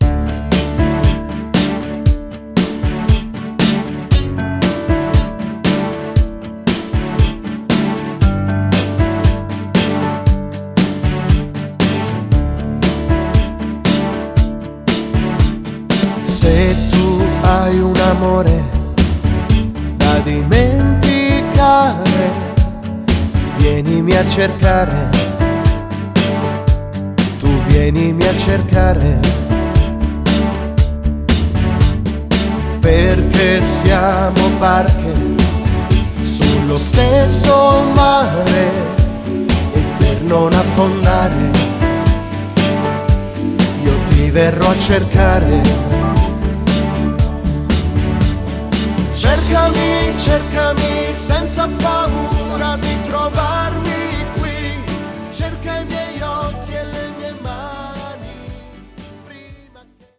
Passeggiata lenta
12 Brani Editoriali + una cover di ballabili per Orchestra